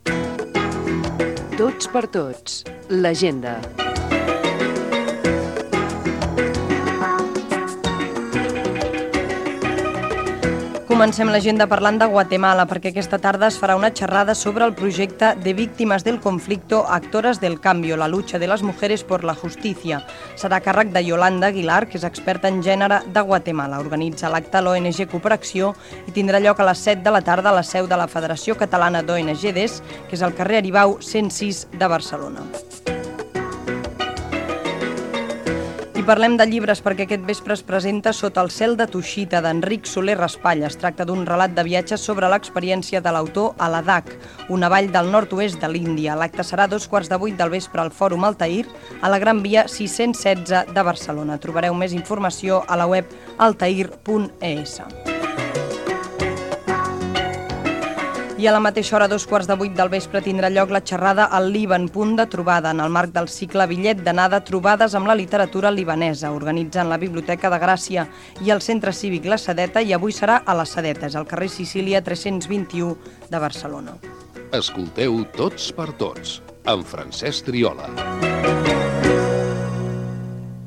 Divulgació
Fragment extret de l'arxiu sonor de COM Ràdio.